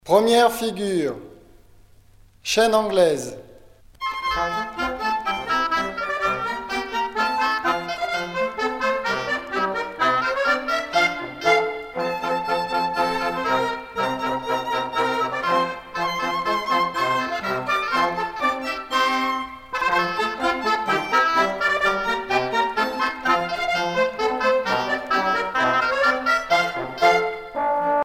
danse : quadrille : chaîne anglaise ;
Pièce musicale éditée